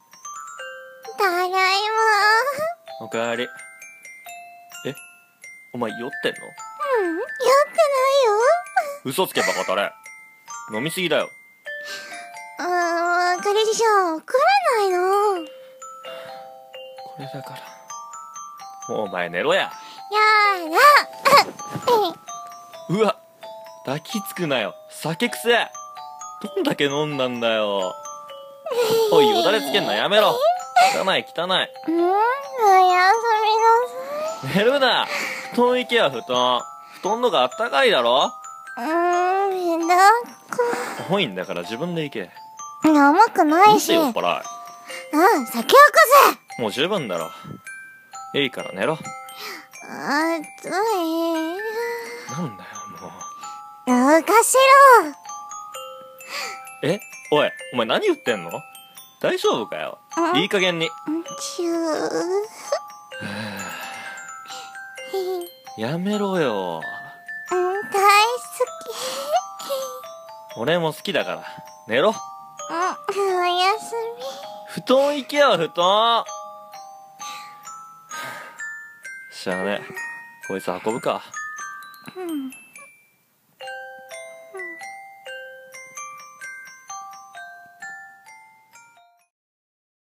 【声劇】酔っ払い彼女 や り ま し た